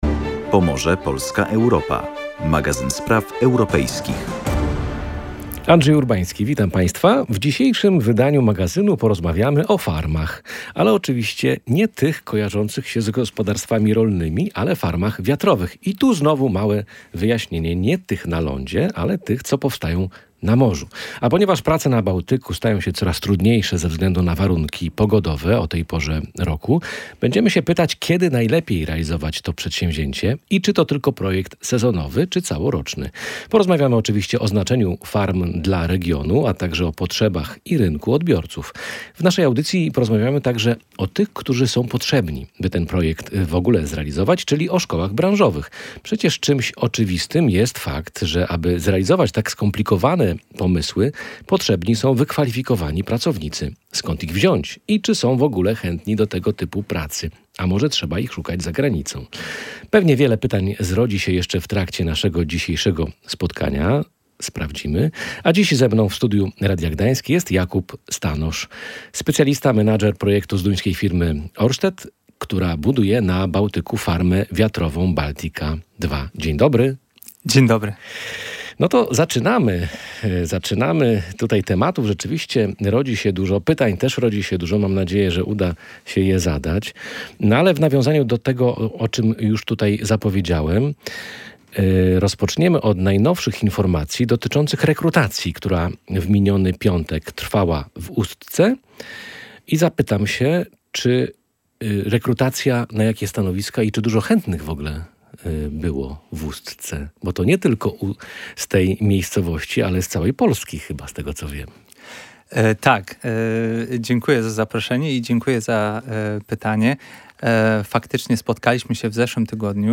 Specjalista wyjaśnia